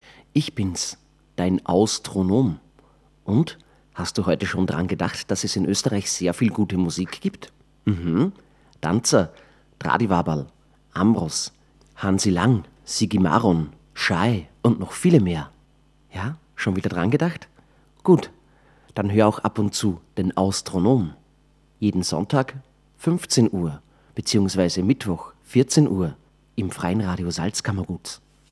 Sendungstrailer